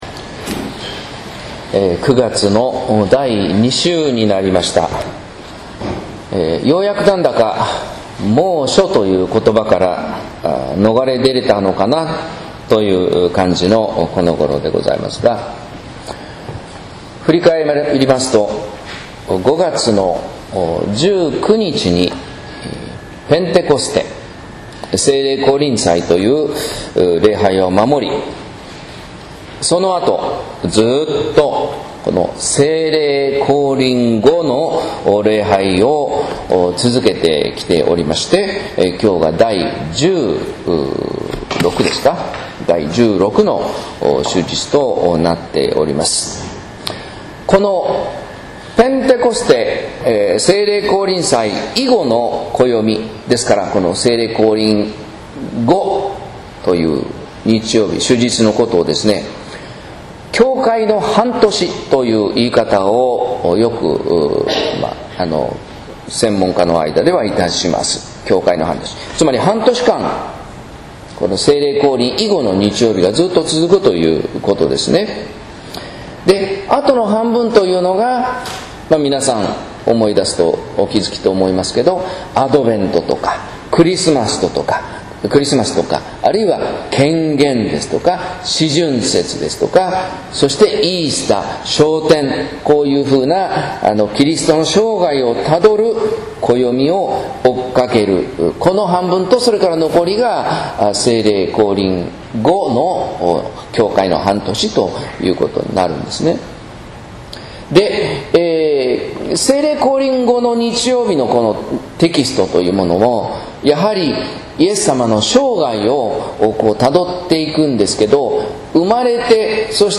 説教「人の努力とイエスの満たし」（音声版）